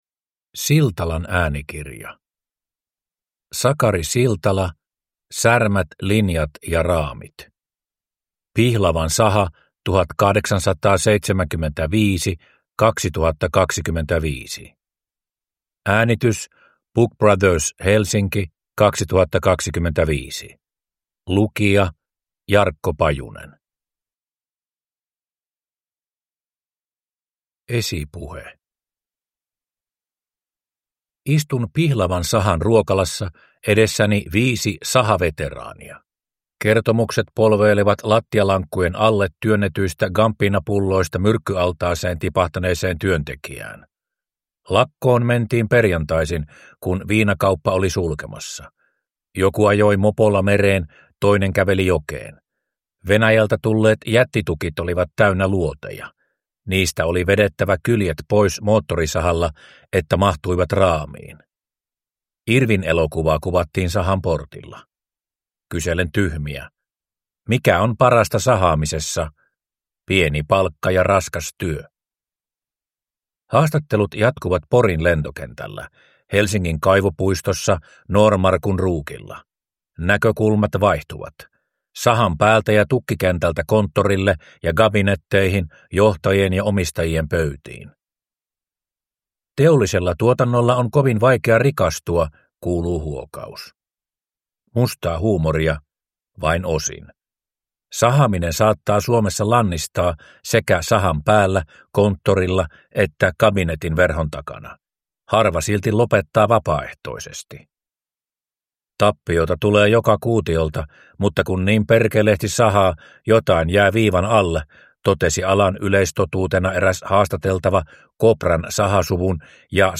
Särmät, linjat ja raamit – Ljudbok